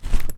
Take_item.ogg